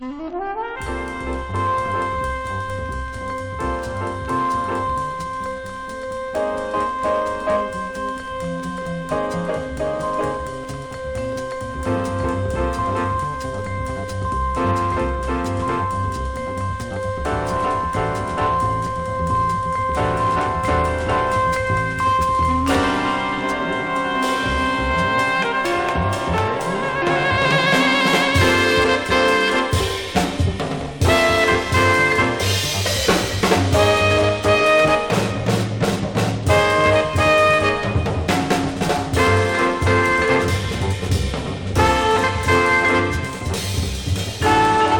Jazz, Bop, Modal, Cool Jazz　USA　12inchレコード　33rpm　Stereo